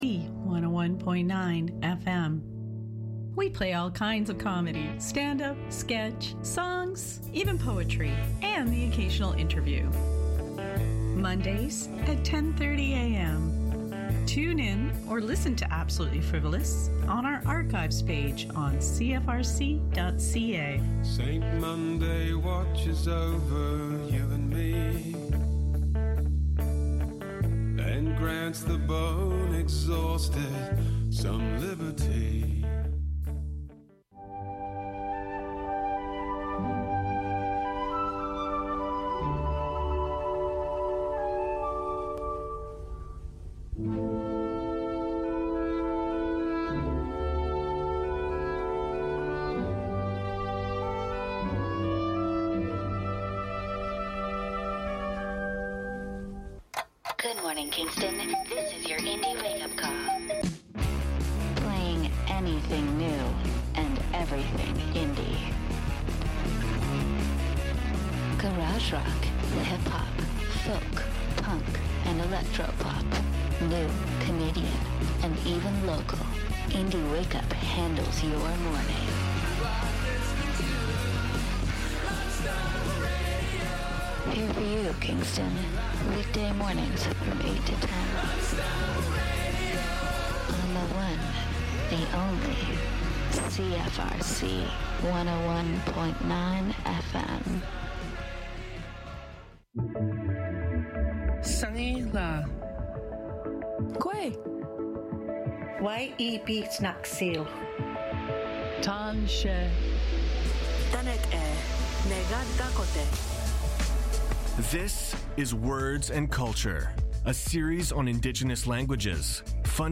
Short, sweet, and fresh - the perfect way to start your morning.